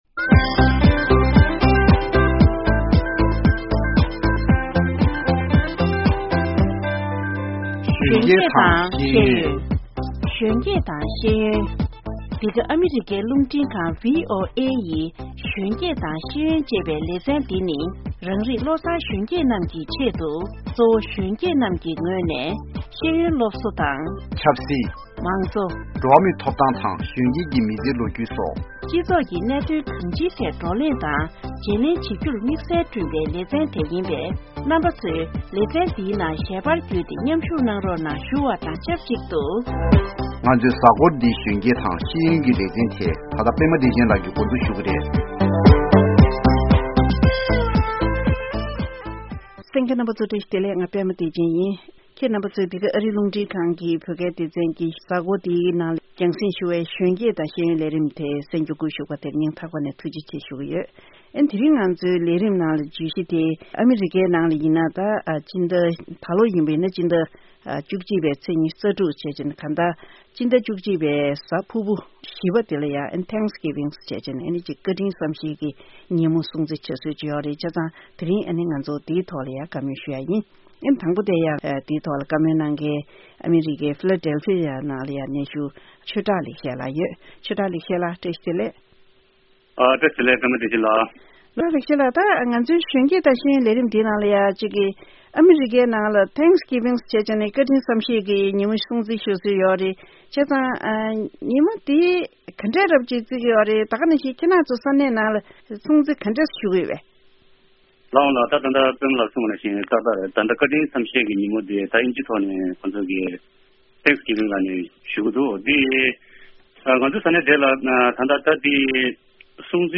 ཨ་མི་རི་ཀར་ལོ་ལྟར་ཕྱི་ཟླ་༡༡་པའི་གཟའ་འཁོར་བཞི་པའི་གཟའ་ཕུར་བུའི་ཉིན་མོ་དེ་ནི་བཀའ་དྲིན་རྗེས་དྲན་གྱི་ཉིན་མོའམ། ཡང་ན་བཀའ་དྲིན་བསམ་ཤེས་ཀྱི་ཉིན་མོར་ངོས་འཛིན་གྱིས་སྲུང་བརྩི་བྱེད་སྲོལ་ཞིག་དར་ཡོད་ཅིང་། གཟའ་འཁོར་འདིའི་གཞོན་སྐྱེས་དང་ཤེས་ཡོན་གྱི་ལས་རིམ་ནང་དུ་ཨ་མི་རི་ཀར་གནས་འཁོད་བོད་པ་གཉིས་ལ་དུས་དྲན་དེ་སྲུང་བརྩི་བྱེད་སྟངས་འདྲ་མིན་ཐོག་ཏུ་གཏམ་གླེང་ཞུས་པ་དེ་གསན་རོགས་གནང་།།